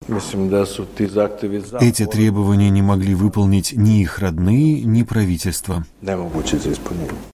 Говорит глава МИД Сербии Ивица Дачич